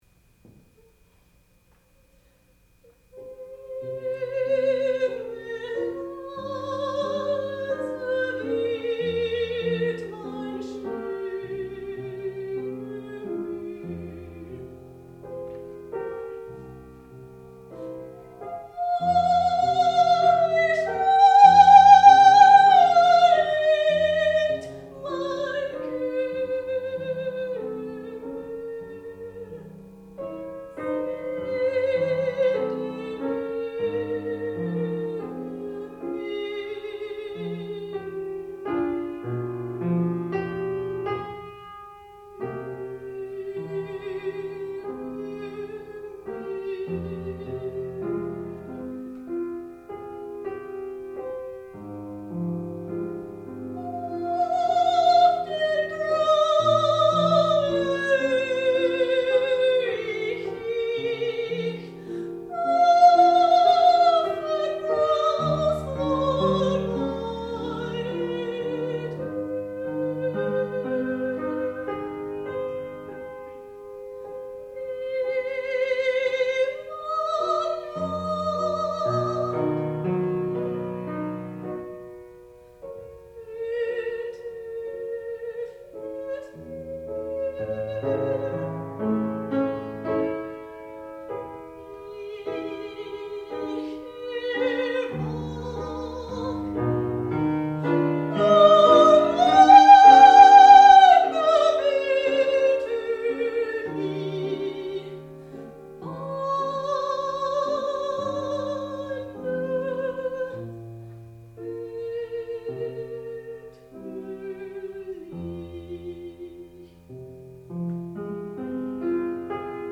sound recording-musical
classical music
soprano
piano
Qualifying Recital